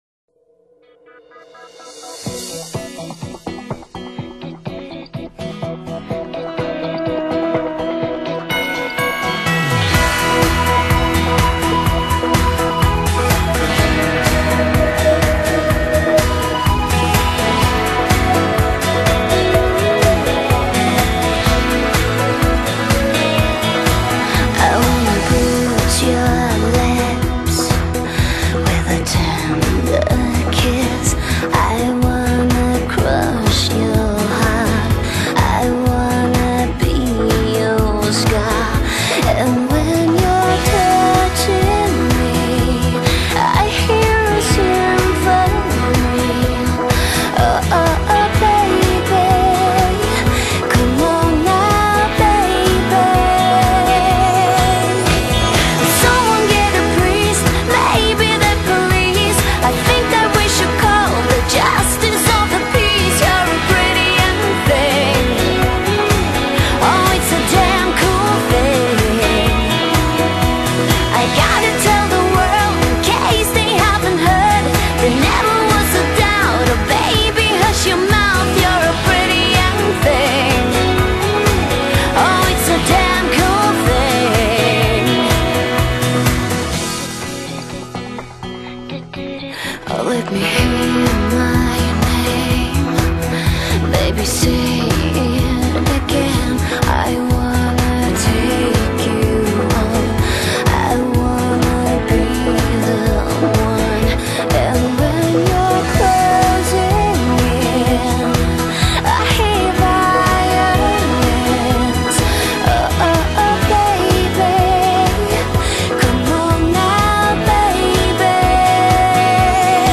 欧美最流行金曲榜
后期母带处理：日本富士山数位工作室HDSP后期处理